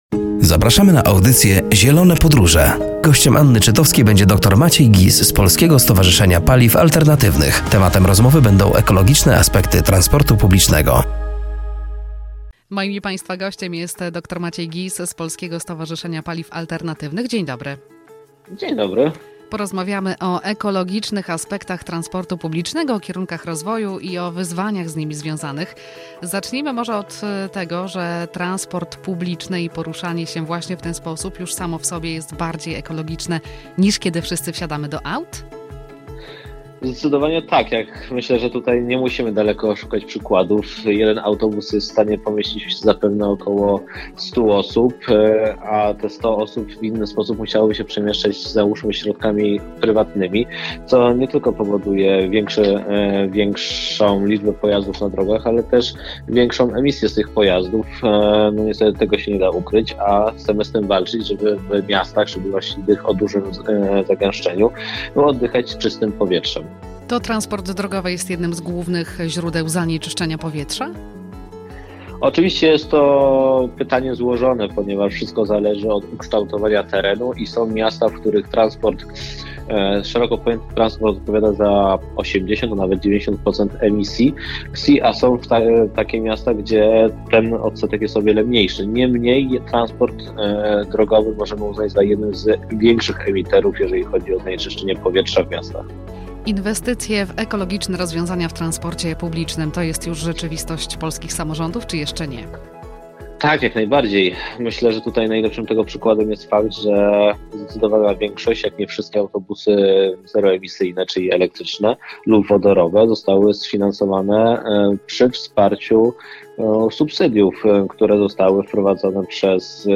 “Zielone Podróże” w środę o g. 10.30 na antenie Radia Nadzieja.